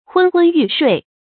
昏昏欲睡 hūn hūn yù shuì
昏昏欲睡发音
成语正音 昏，不能读作“hún”。